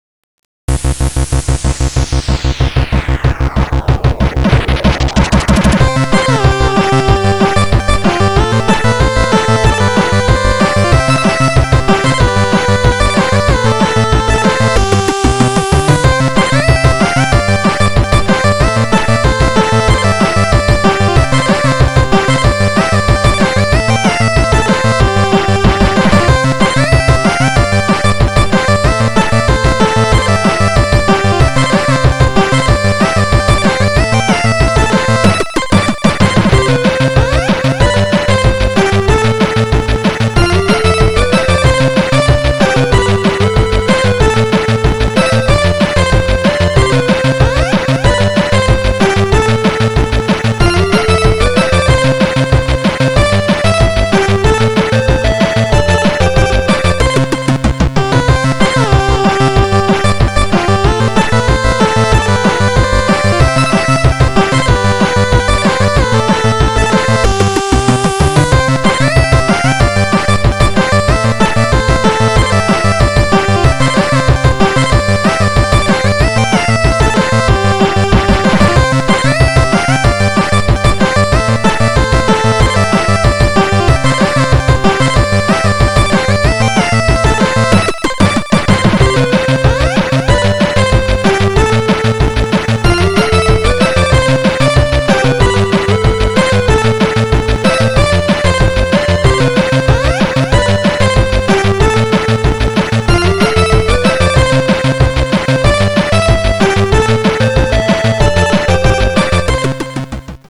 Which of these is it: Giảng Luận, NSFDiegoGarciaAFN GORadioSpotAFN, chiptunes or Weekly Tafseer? chiptunes